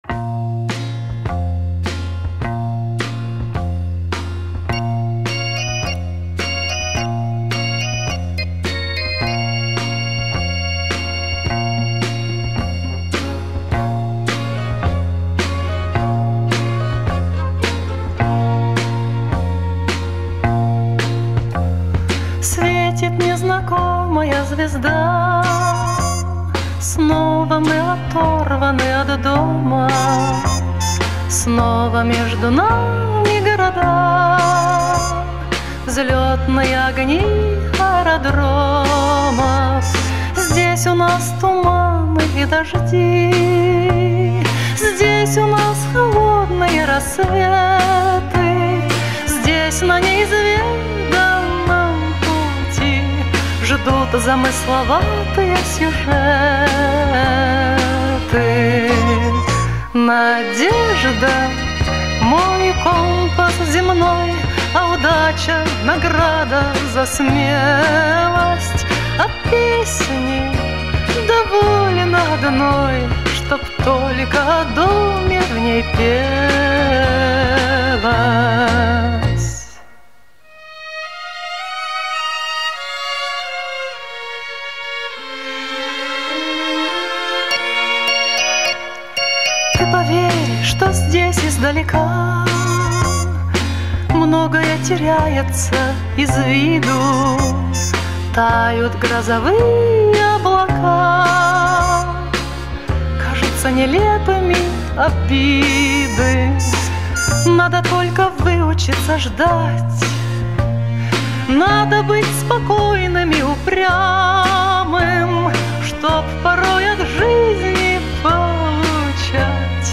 наполнена теплом и светлой меланхолией